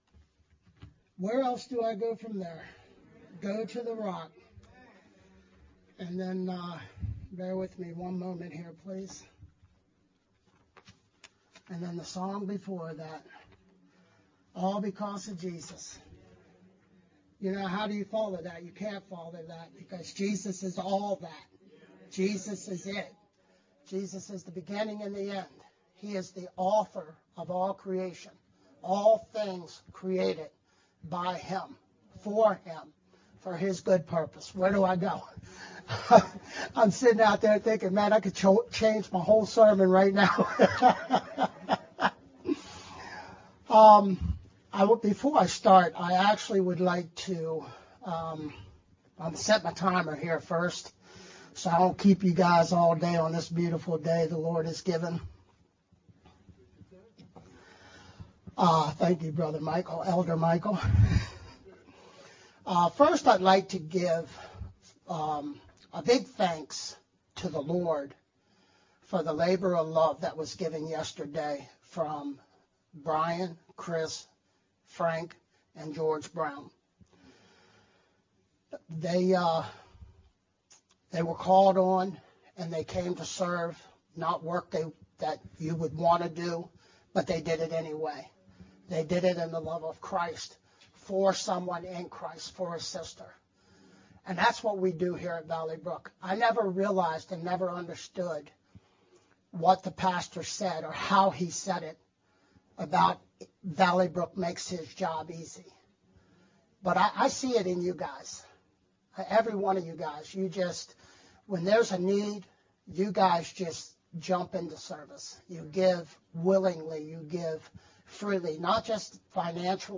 May-21st-VBCC-Sermon-only-MP3-CD.mp3